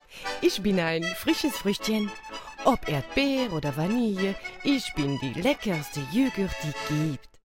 Sprecherin für Hörspiel, Hörbuch, Werbung, Trickstimme, Audio-Guides, Games, Mehrwertdienste, Präsentationen, Synchron
rheinisch
Sprechprobe: Industrie (Muttersprache):